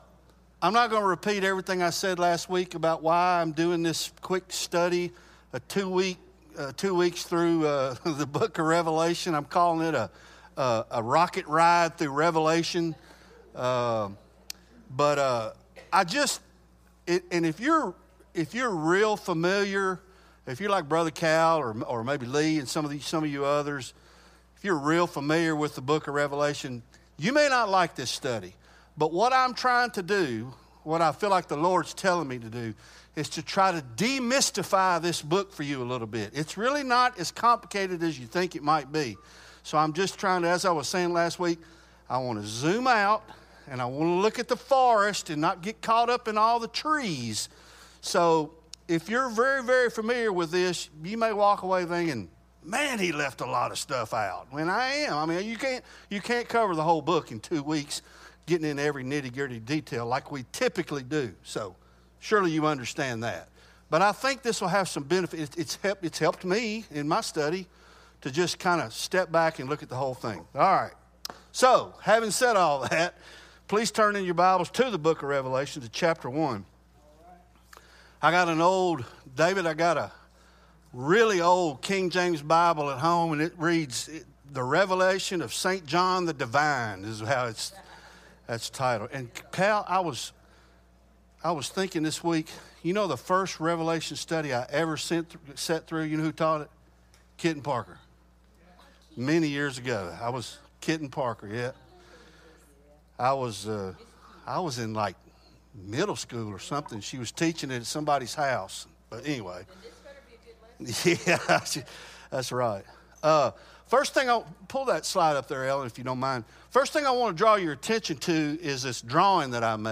Sermons Archive - Page 13 of 311 - 2nd Mile Church